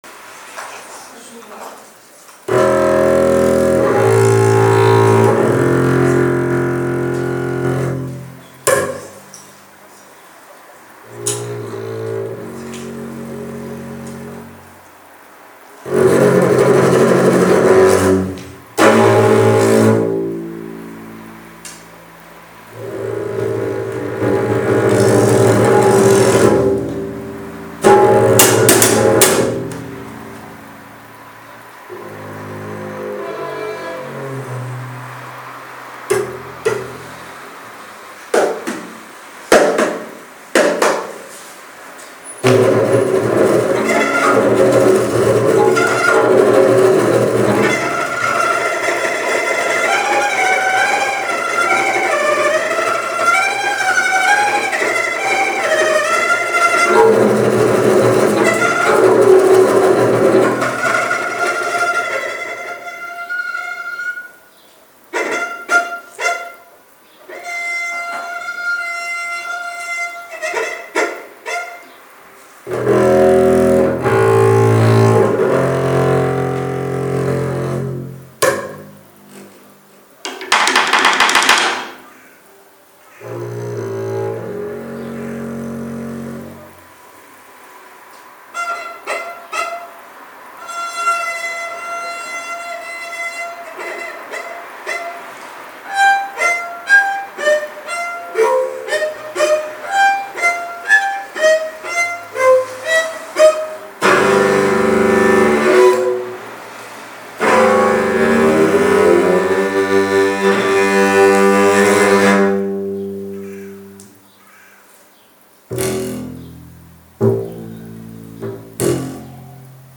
• Category: Contrabass